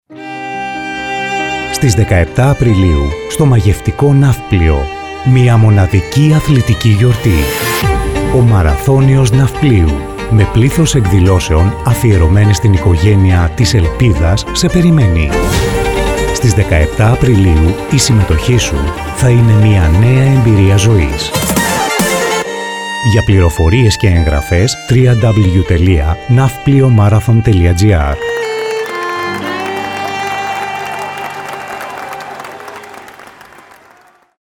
εδώ το Ραδιοφωνικό ΣΠΟΤ της διοργάνωσης.
Marathon_spot_2022_vrs1.mp3